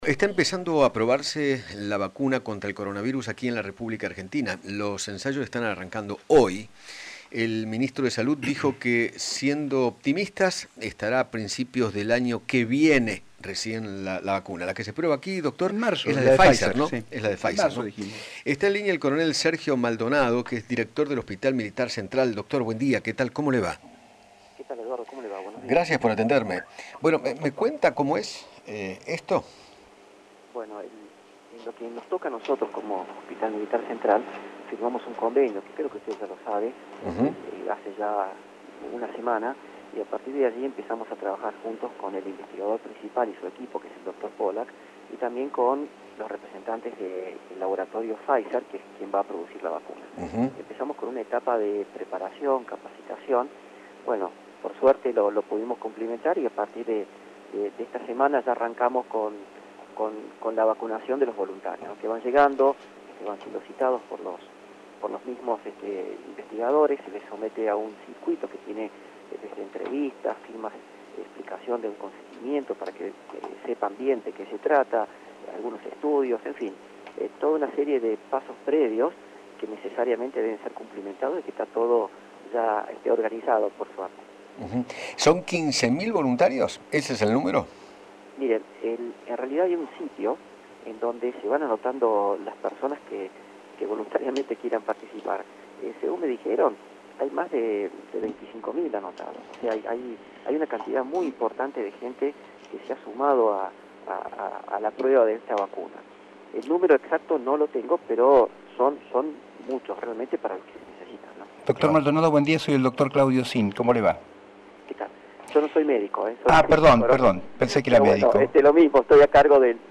Eduardo Feinmann dialogó con